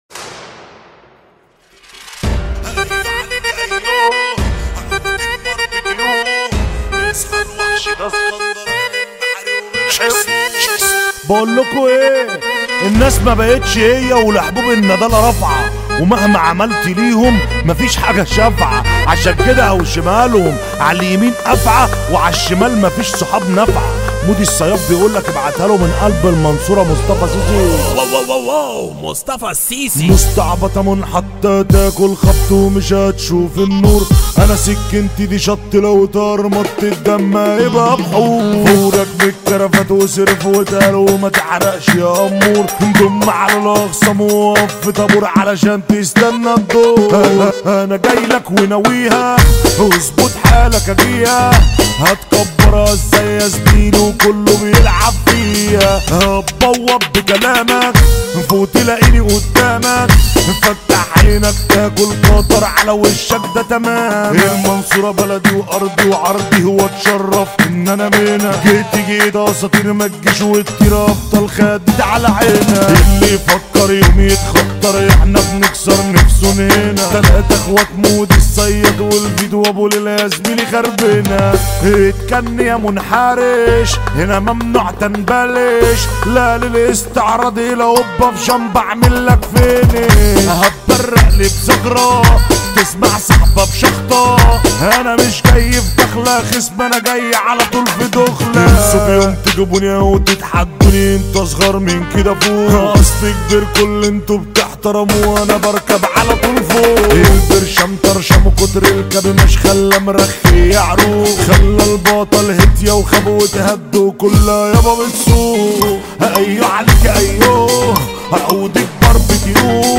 اغانى مهرجانات